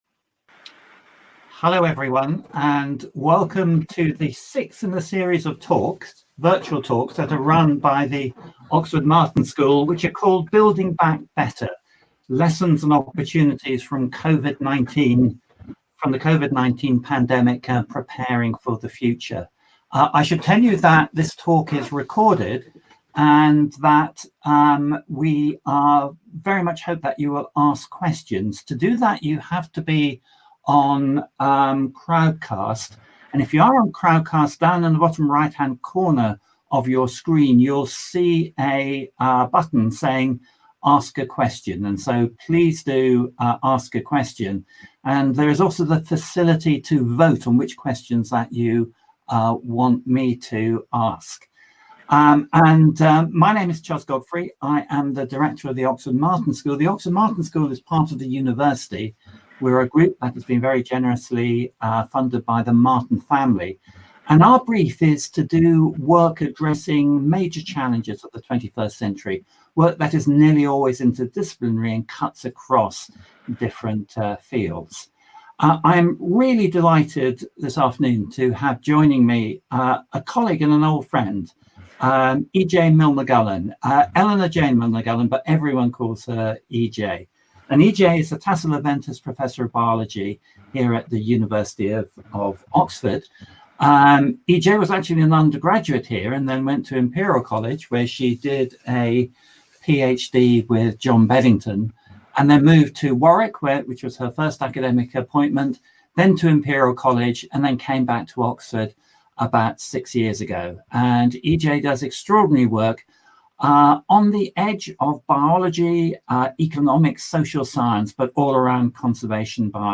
Public Lectures and Seminars